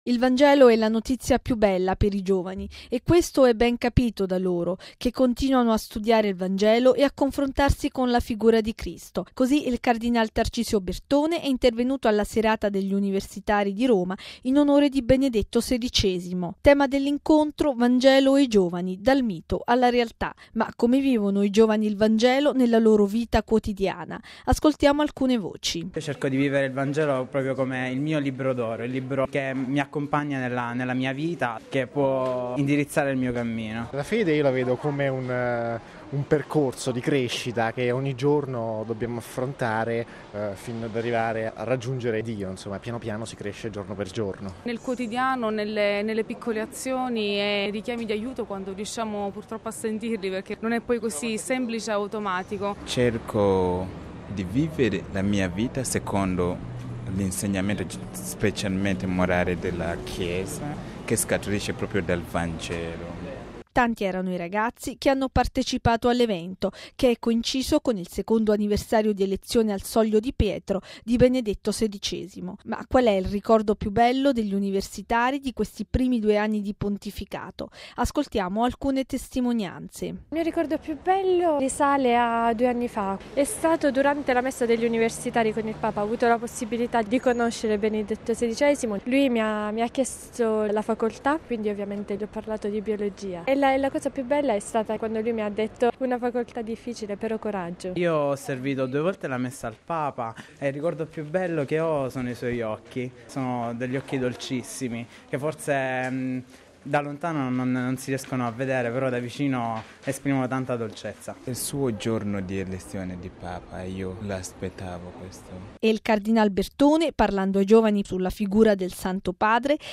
Al Teatro Argentina di Roma la festa degli universitari romani per Benedetto XVI
Ma come vivono i giovani il Vangelo nella loro quotidianità? Ascoltiamo alcune voci: